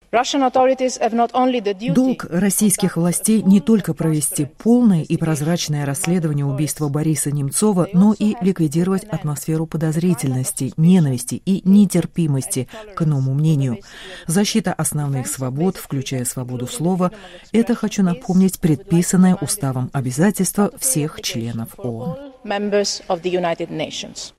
Говорит верховный представитель ЕС по внешней политике Федерика Могерини